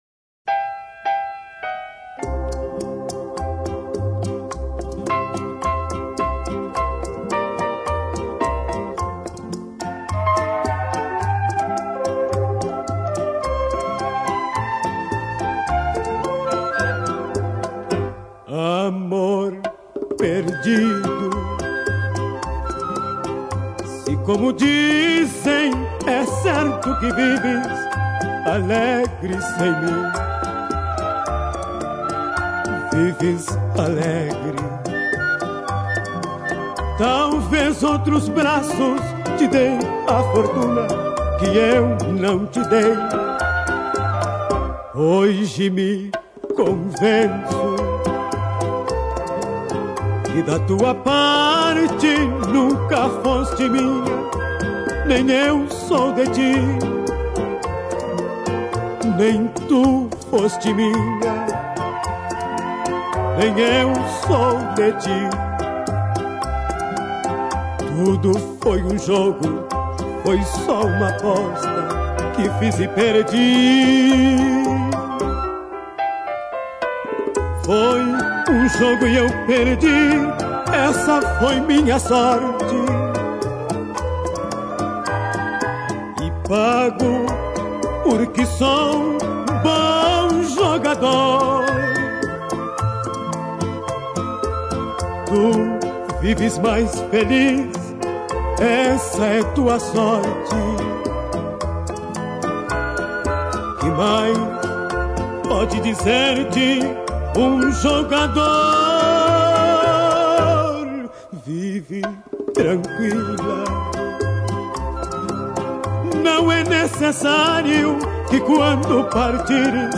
Boleros